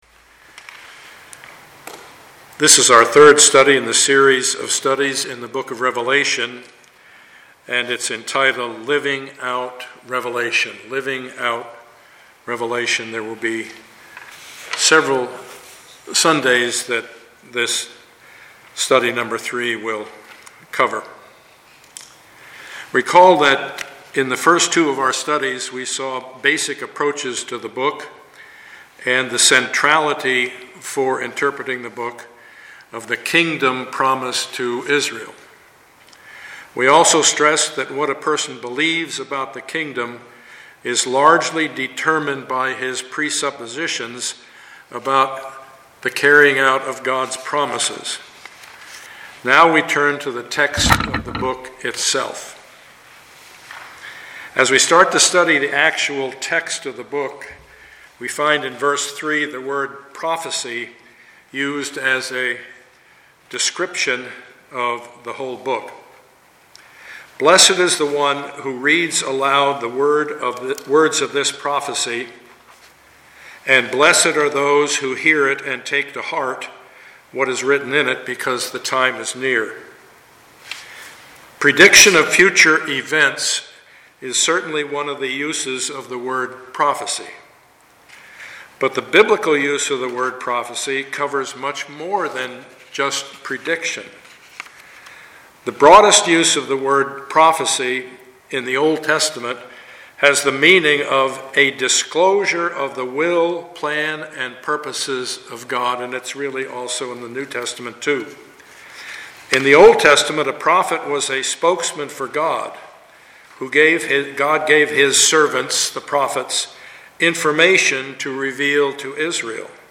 Passage: Revelation 1:1-8 Service Type: Sunday morning